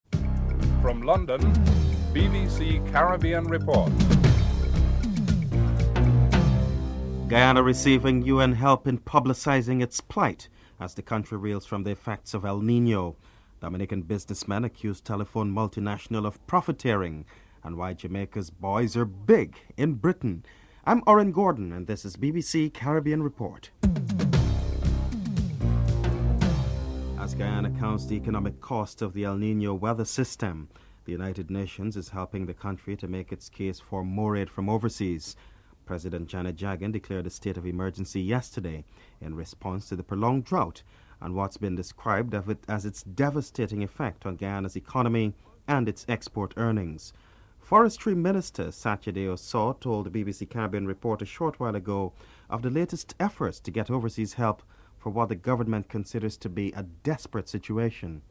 2. As Guyana counts the economic costs of El Nino, the UN is helping in its appeal for more aid from overseas. Forestry Minister Satyadeow Sawh comments on the latest efforts to get overseas help (00:26-01:59)
9. Recap of top stories (14:55-15:15)